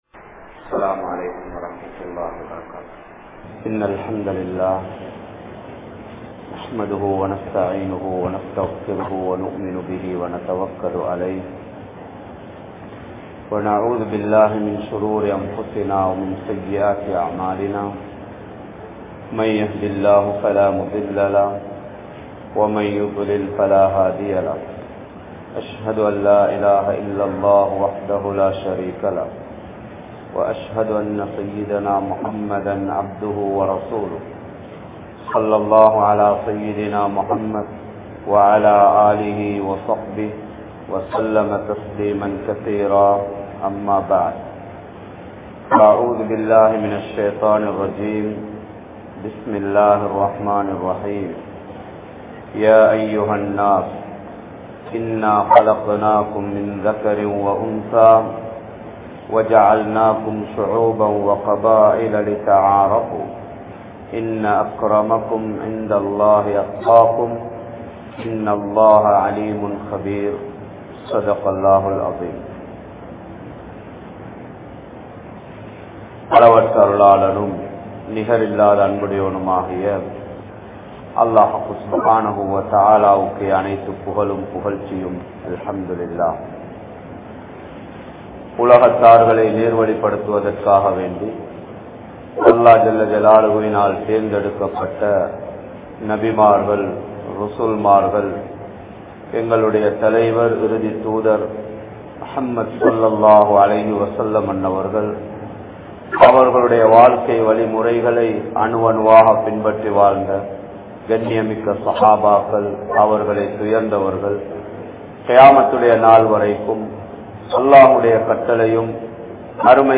Thaqwa | Audio Bayans | All Ceylon Muslim Youth Community | Addalaichenai